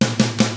146ROCK I1-L.wav